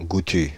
Ääntäminen
Synonyymit gouteux Ääntäminen Paris: IPA: [ɡu.ty] France (Île-de-France): IPA: /ɡu.ty/ Haettu sana löytyi näillä lähdekielillä: ranska Käännös Ääninäyte Adjektiivit 1. tasty US 2. yummy US 3. delicious US Suku: m .